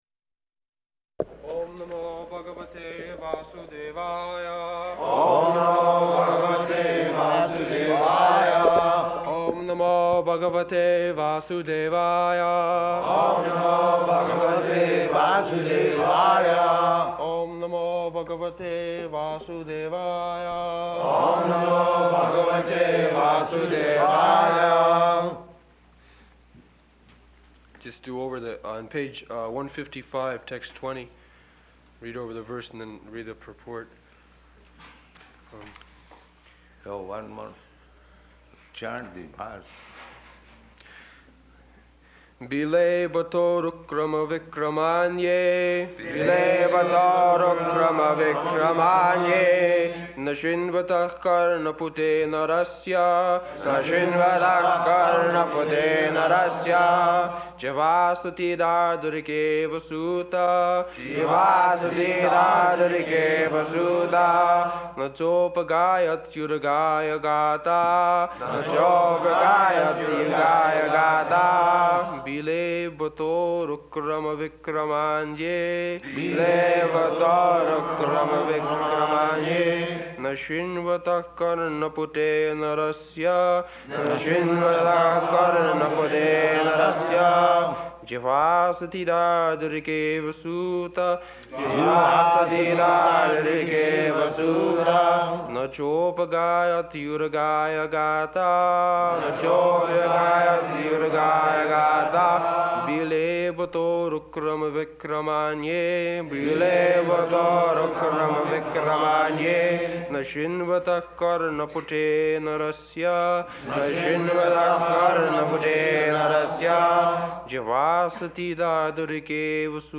June 17th 1972 Location: Los Angeles Audio file
[leads chanting of verse] [Prabhupāda and devotees repeat] Just do over that...